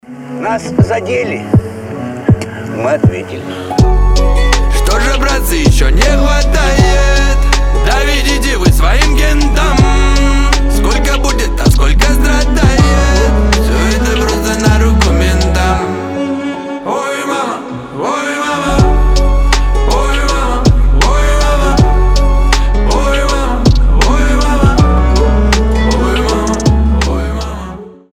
• Качество: 320, Stereo
пацанские
скрипка